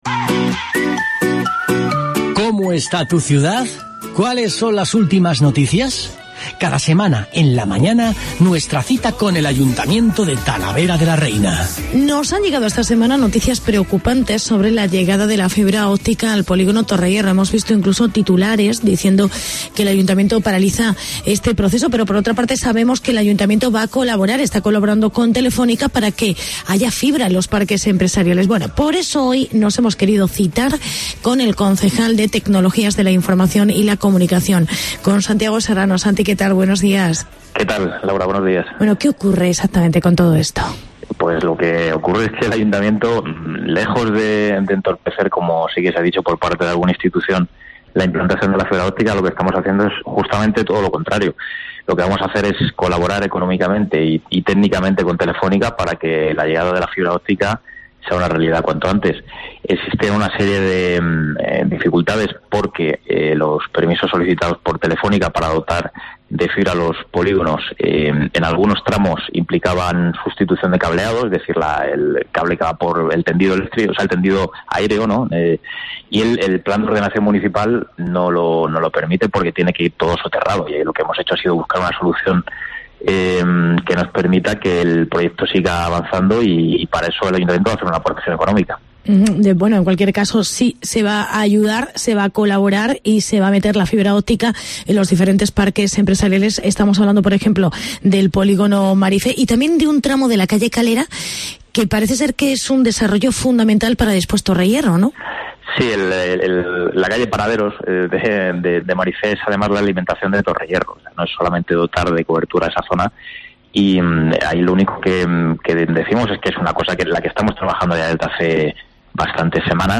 Entrevista con el concejal Santiago Serrano